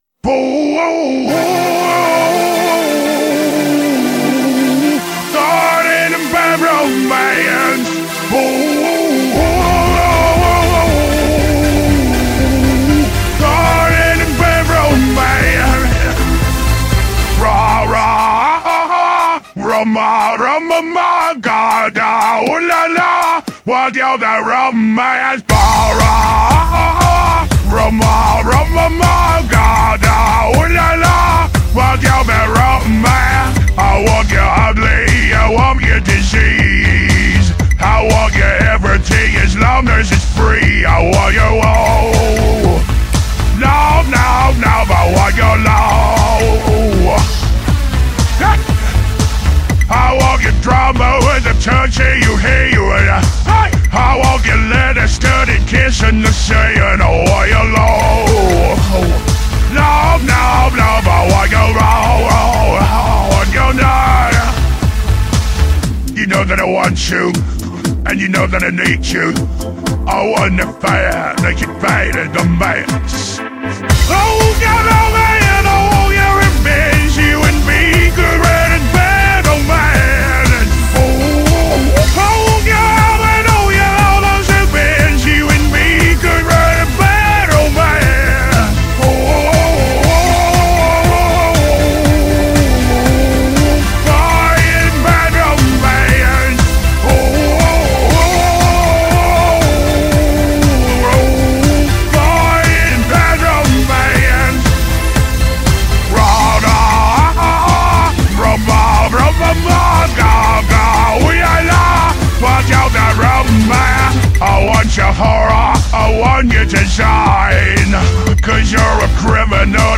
I am having way much more fun with this AI voice thingamajig than I expected.